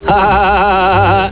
laff.wav